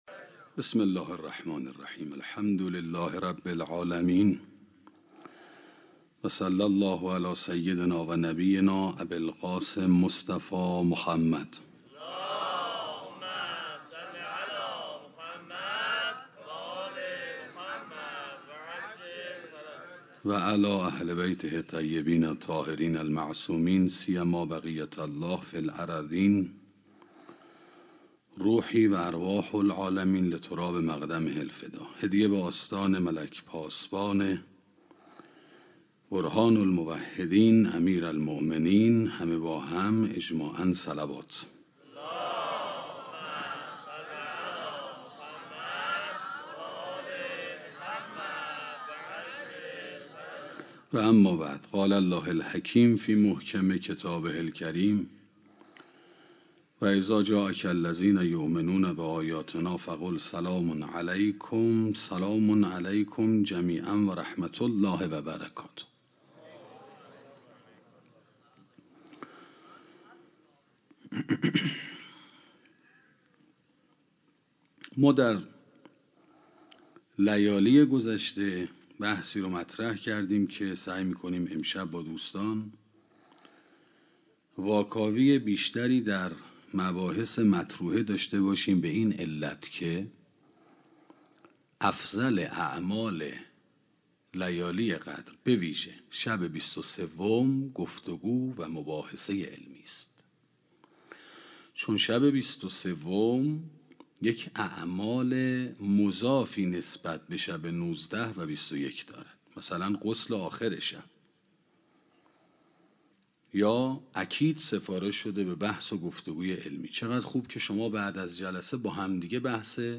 سخنرانی تصویر نظام هستی 5 - موسسه مودت